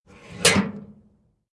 Converted sound effects
mailbox_close_2.ogg